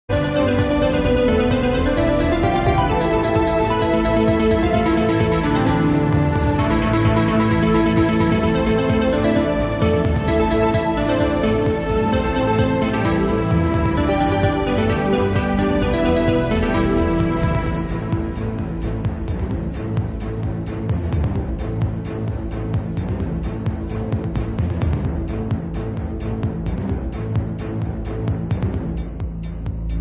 [Lo-Fi preview] Remixers Website